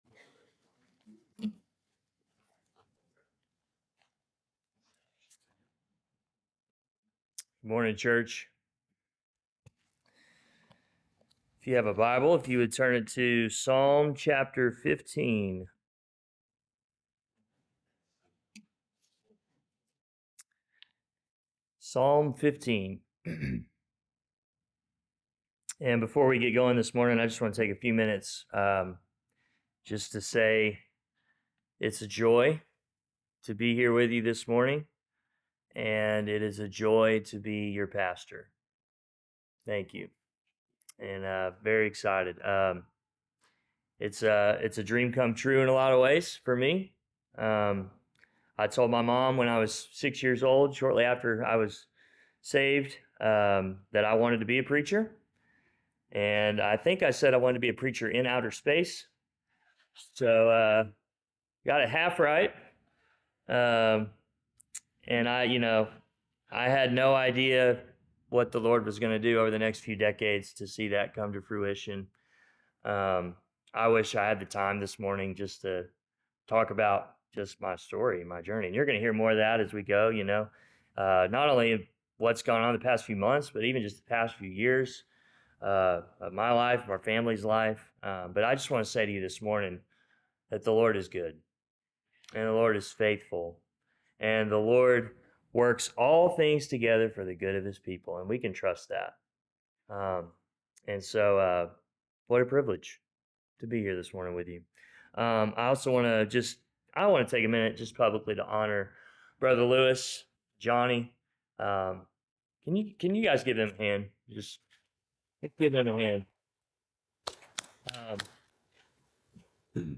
Sermons | Mascot Baptist Church